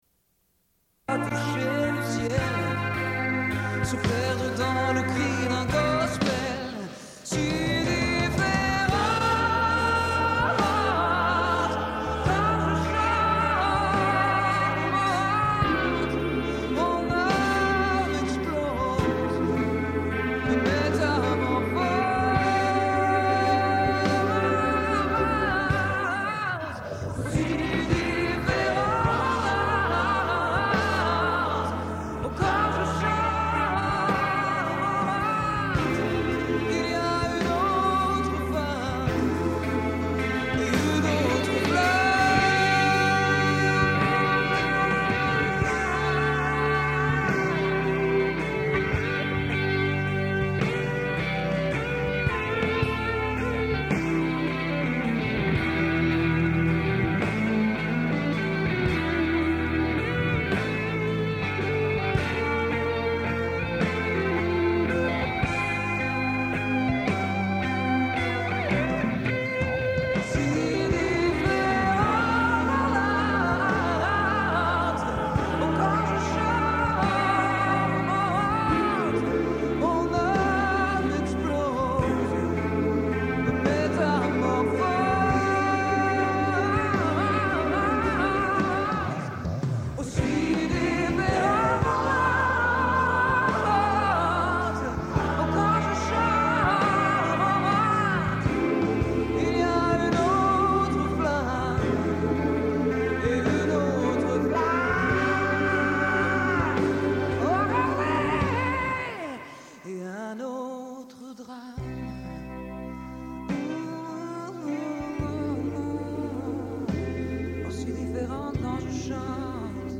Lecture du texte du personnel.
Radio Enregistrement sonore